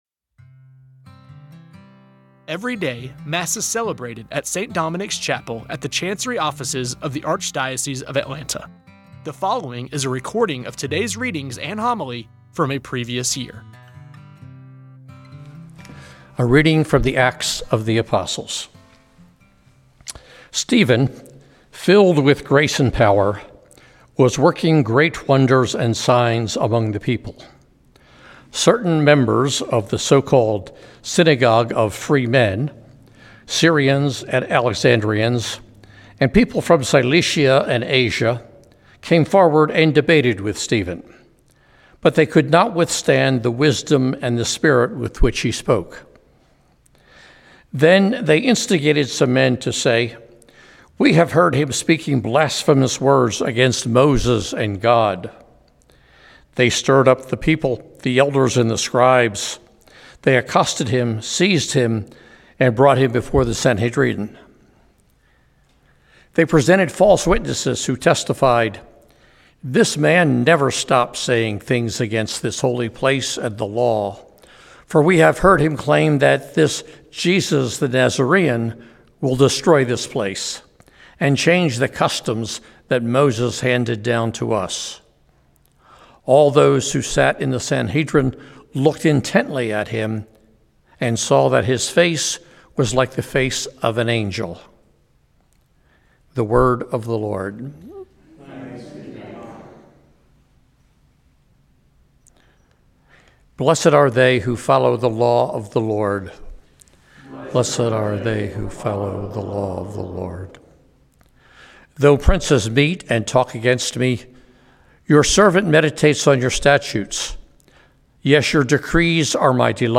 Every day, Mass is celebrated at St. Dominic’s Chapel at the Chancery Offices of the Archdiocese of Atlanta. The following is a recording of today’s readings and homily from a previous year. You may recognize voices proclaiming the readings and homilies as employees, former employees, or friends of the Archdiocese.
Today’s homily is given by Bishop Joel Konzen from April 24, 2023.